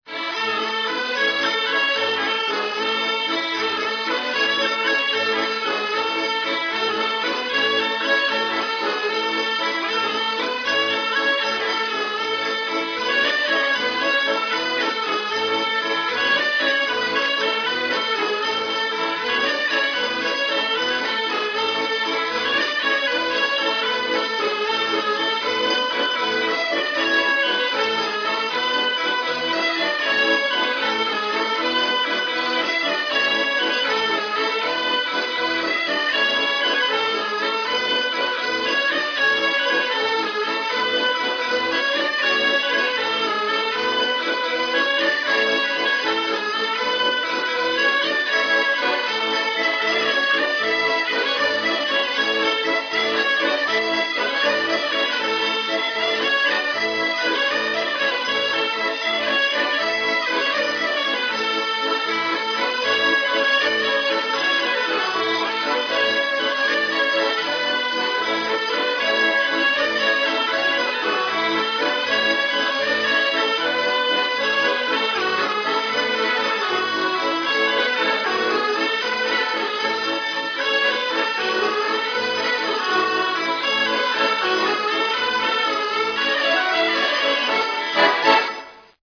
Danses du Limousin